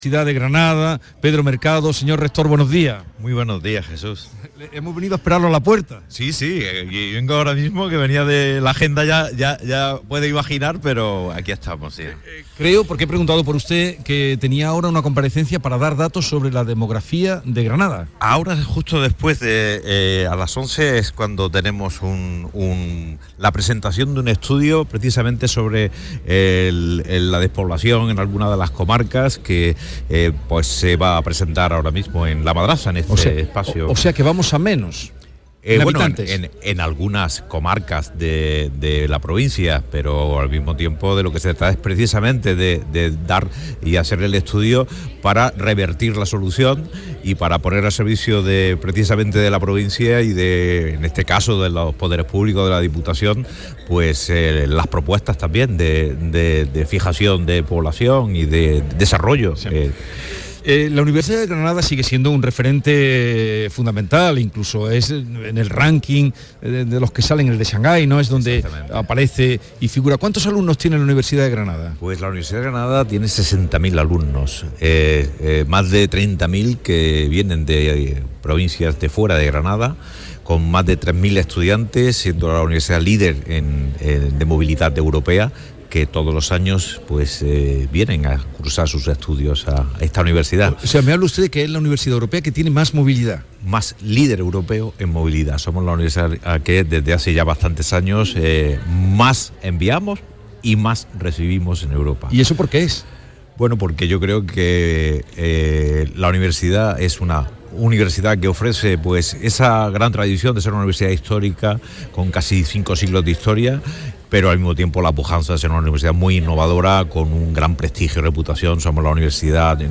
«La mañana de Andalucía» de Canal Sur Radio entrevista al rector Pedro Mercado